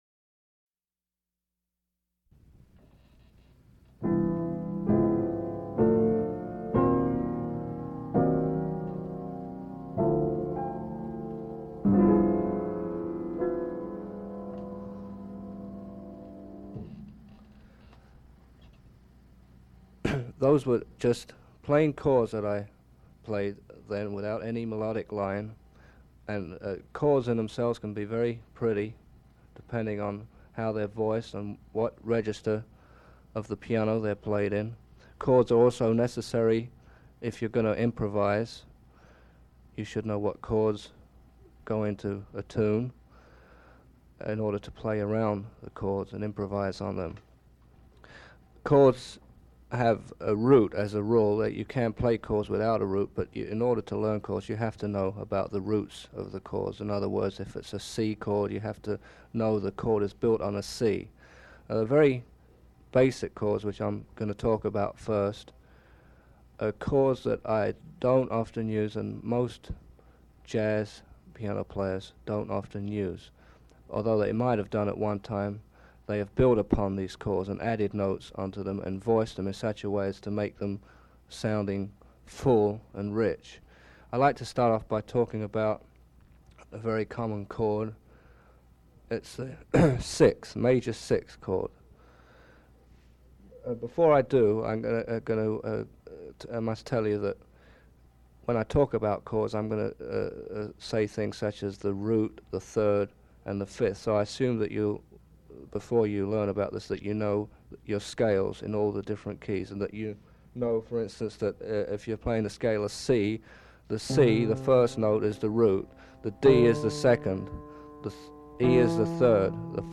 Item from Leonard Feather Collection: Victor Feldman gives a lesson on piano chords during an interview. Victor Feldman was a jazz musician.